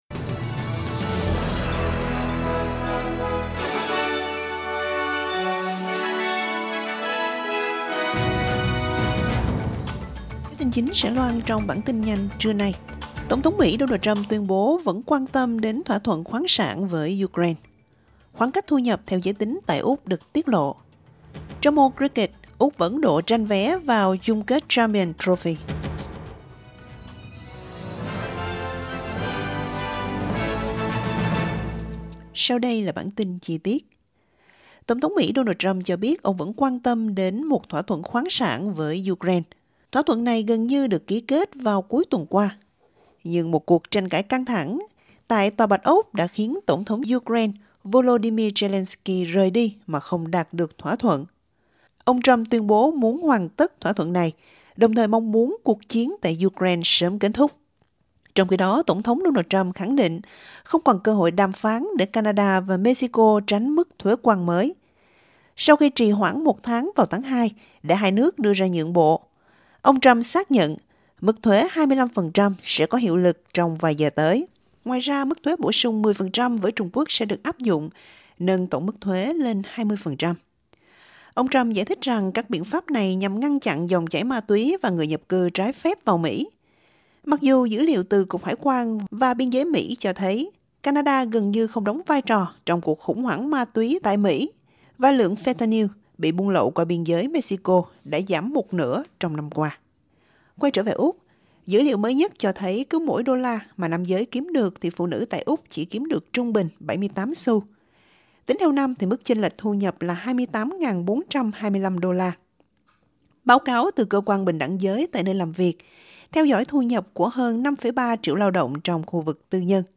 Vietnamese news bulletin Source: AAP